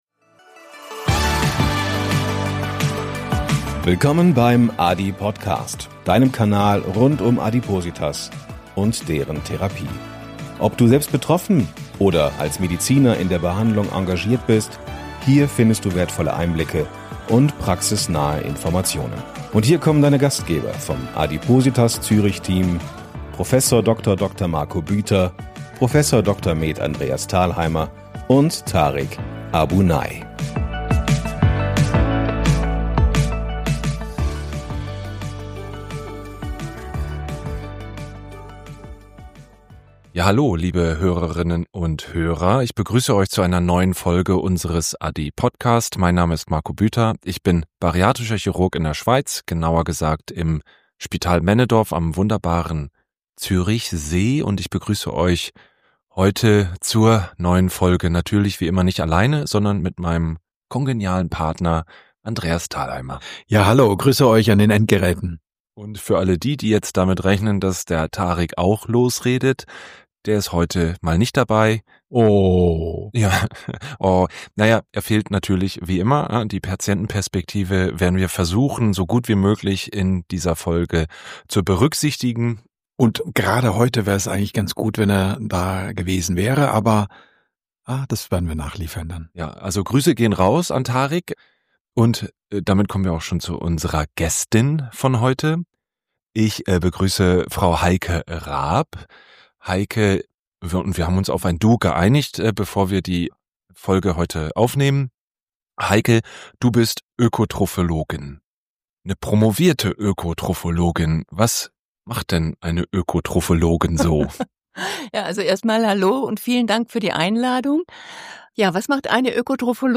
Ein Gespräch voller praktischer Tipps, wissenschaftlicher Einordnung und klarer Worte zur Rolle von Protein, Dumping-Syndrom und alltagstauglicher Ernährung.